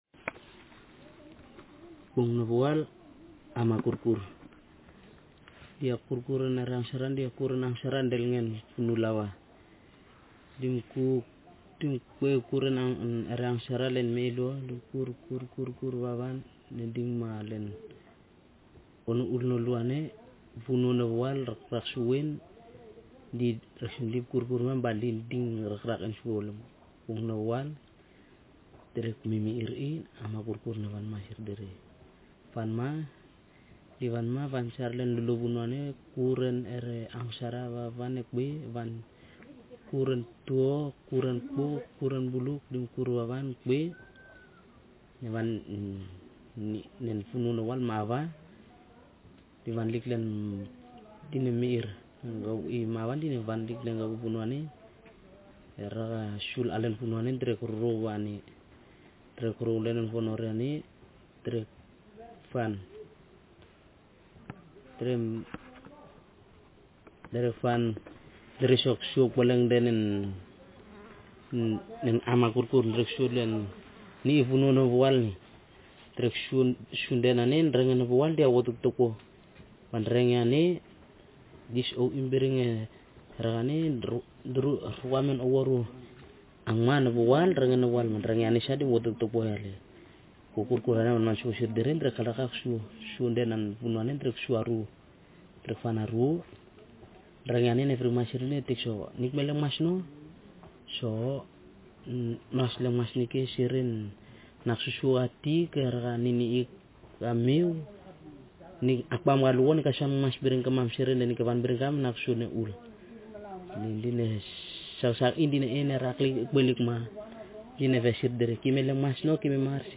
Speaker sex m Text genre traditional narrative